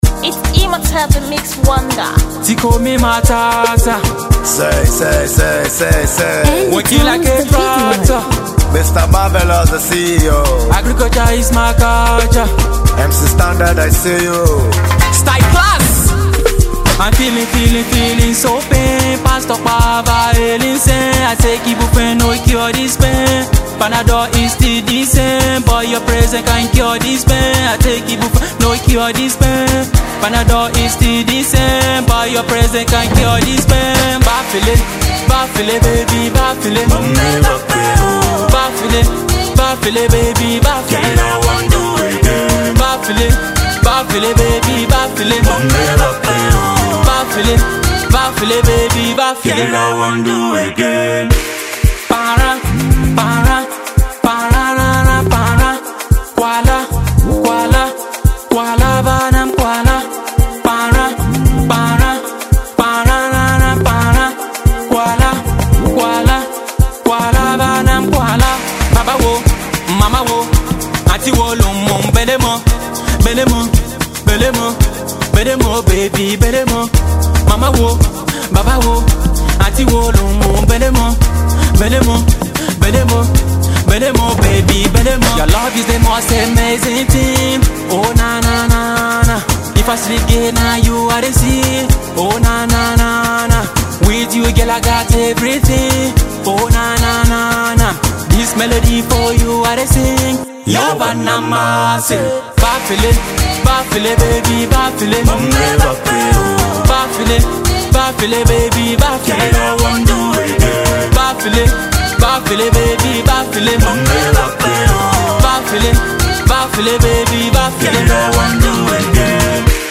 He is a Benue indigenous singer/raper